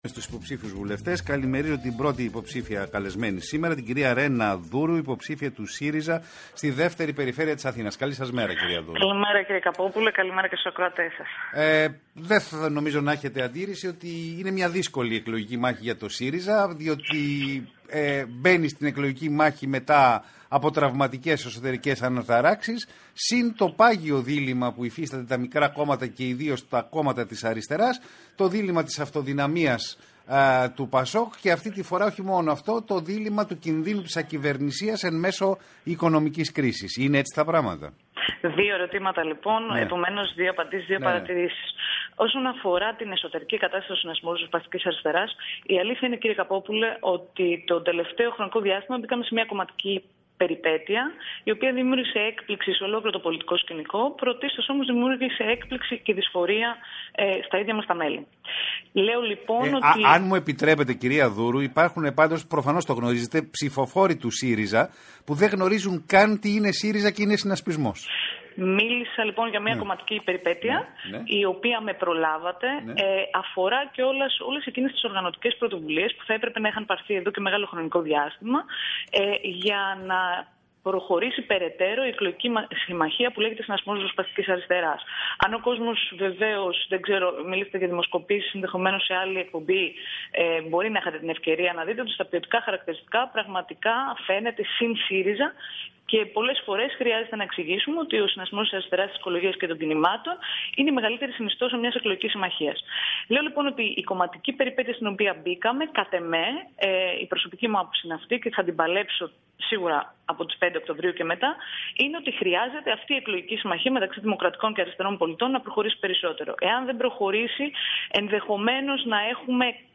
Η Ρένα Δούρου στο ραδιοφωνικό σταθμό ΝΕΤ 105.8